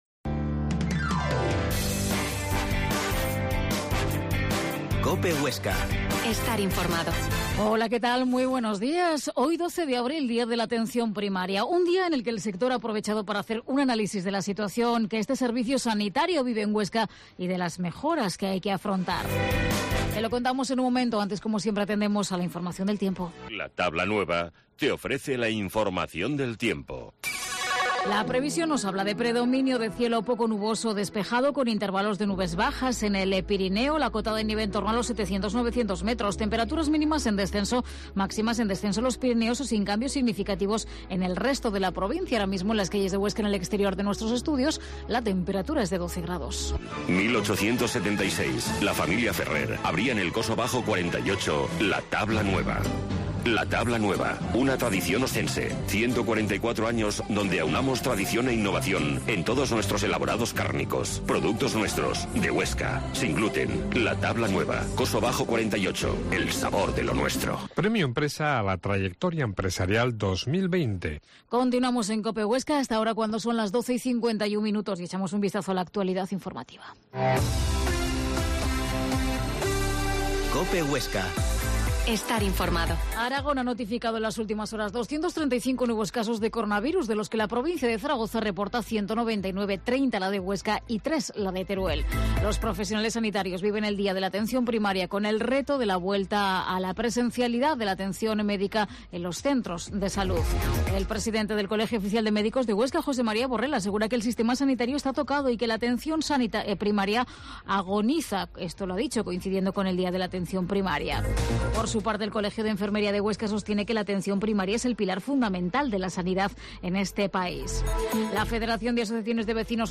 Herrera en COPE Huesca 12.50h Entrevista